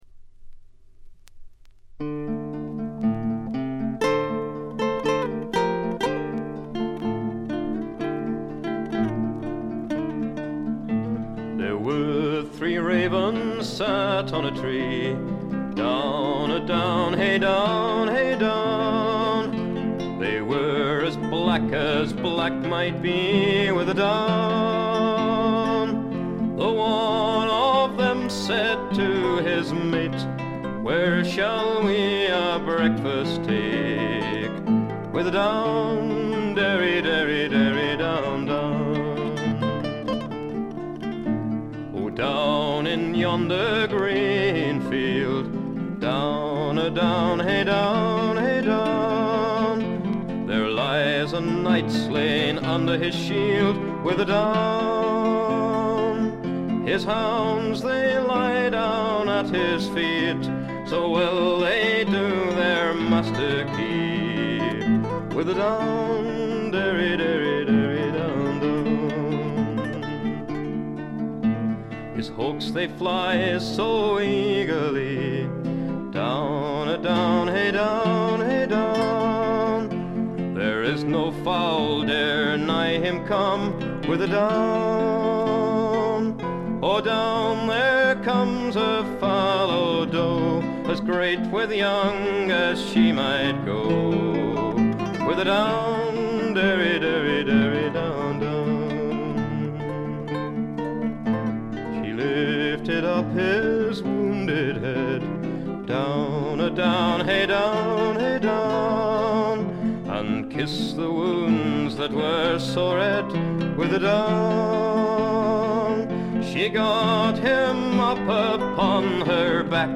静音部でチリプチ少々。ところどころで散発的なプツ音。
試聴曲は現品からの取り込み音源です。
Guitar, Lead Vocals
Guitar, Banjo, Mandolin, Vocals
Flute, Vocals